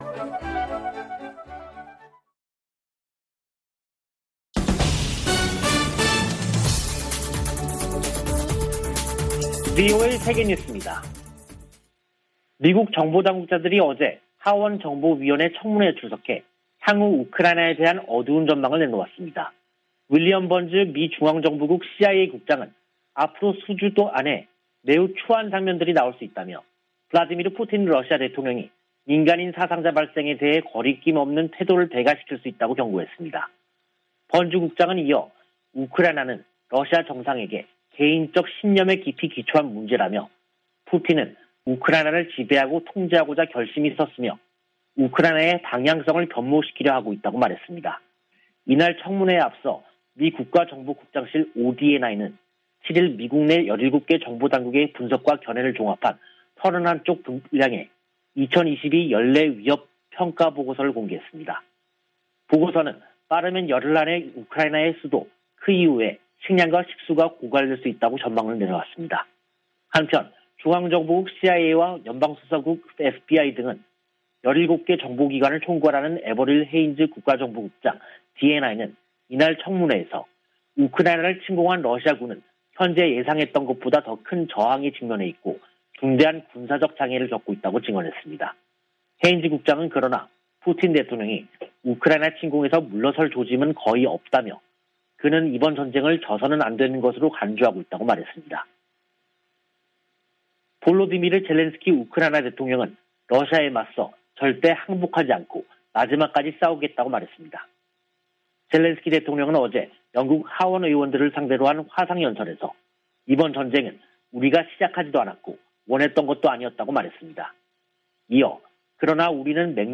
VOA 한국어 간판 뉴스 프로그램 '뉴스 투데이', 2022년 3월 9일 2부 방송입니다. 북한이 미국과 동맹국을 겨냥해 핵과 재래식 능력을 지속적으로 확장하고 있다고 미 국가정보국장이 평가했습니다. 북한이 신형 ICBM을 조만간 시험발사할 수 있다는 미군 고위 당국자의 전망이 나왔습니다. 한국 20대 대통령 선거가 9일 실시됐습니다.